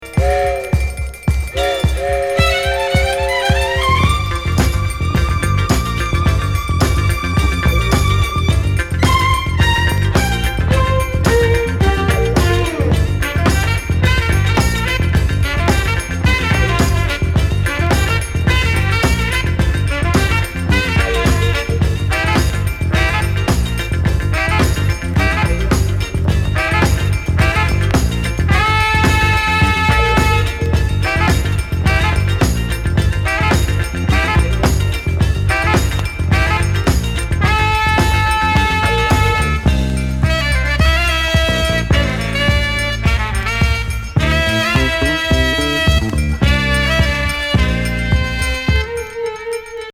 電車ファンク・クラシック！